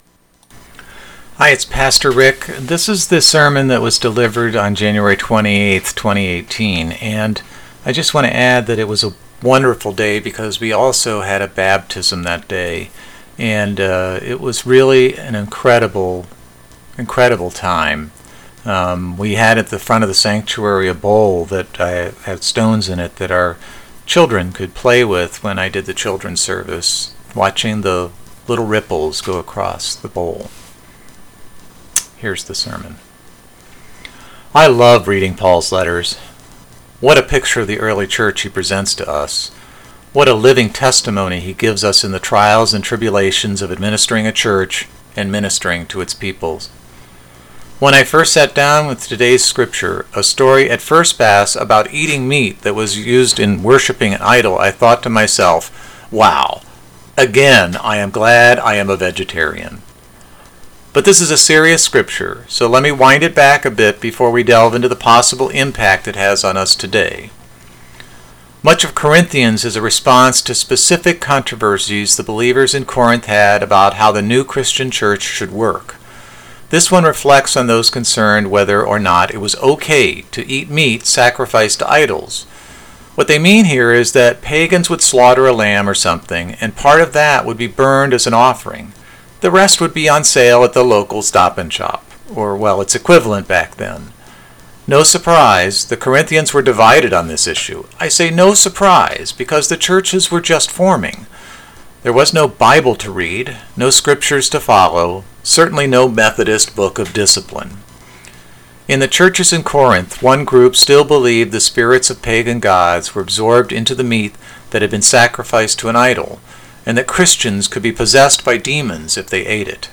January 28, 2018 Sunday Service Audio
Sunday Service January 28, 2018